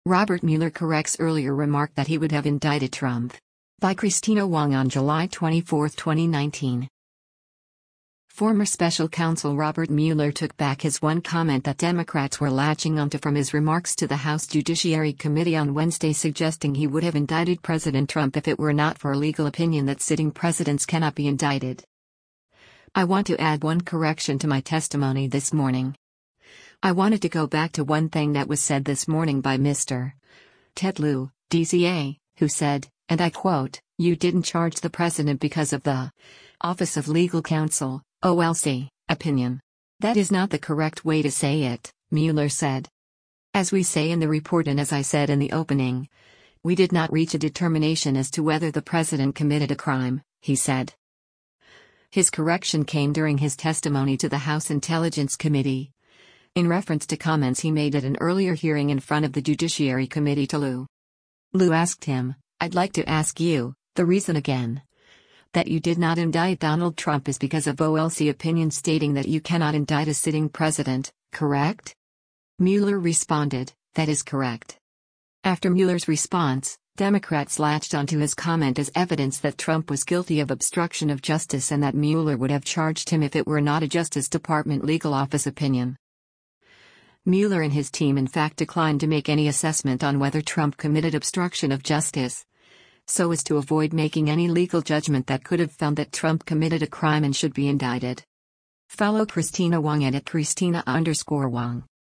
His correction came during his testimony to the House Intelligence Committee, in reference to comments he made at an earlier hearing in front of the Judiciary Committee to Lieu.